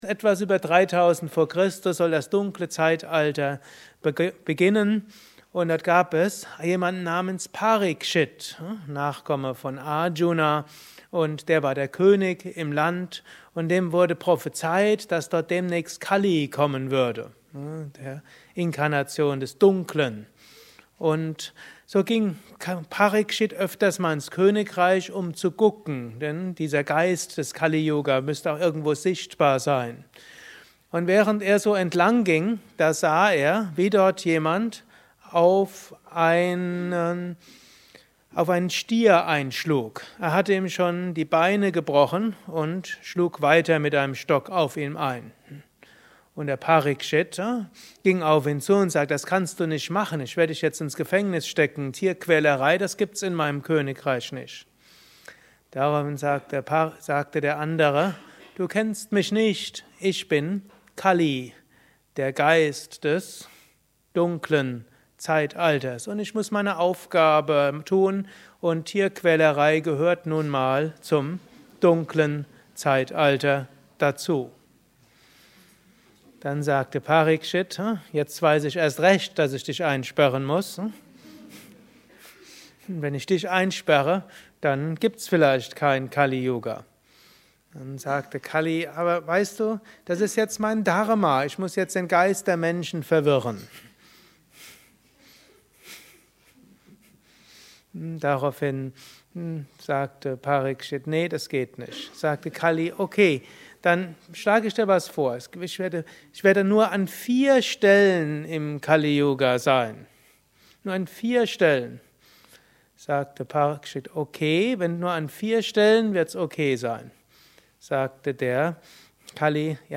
Höre einen kurzen Beitrag zur Geschichte über das Zeitalter. Dies ist ein kurzer Vortrag als Inspiration für den heutigen Tag
eine Aufnahme während eines Satsangs gehalten nach einer Meditation im Yoga Vidya Ashram Bad Meinberg.